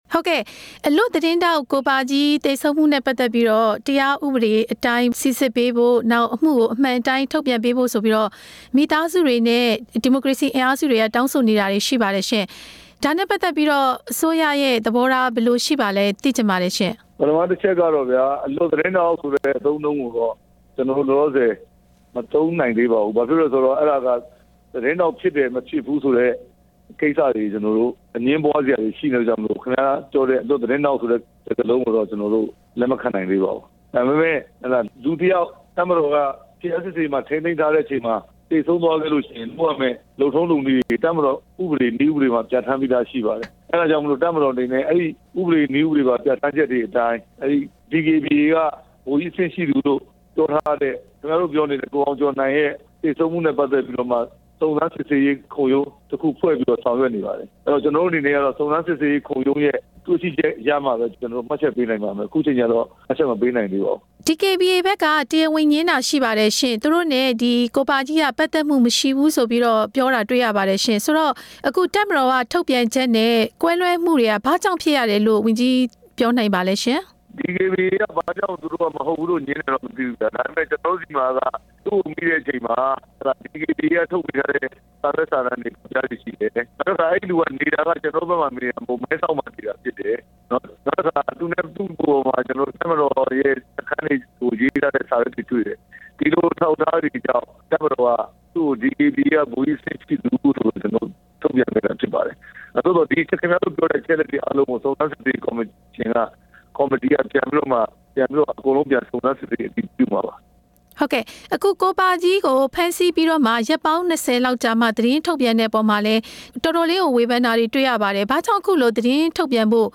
ပြန်ကြားရေးဝန်ကြီး ဦးရဲထွဋ်ကို မေးမြန်းချက် နားထောင်ရန်